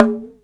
TABLA 12.WAV